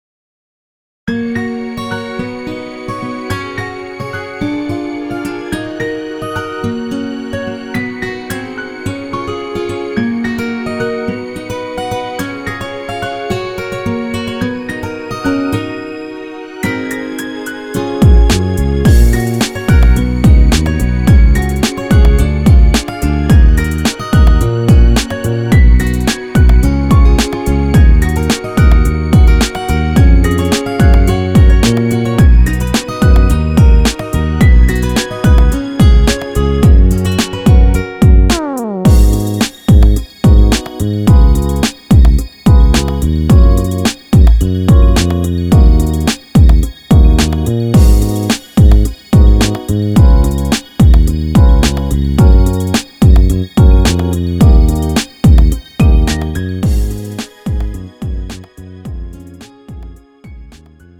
음정 (여자)
장르 가요 구분 Lite MR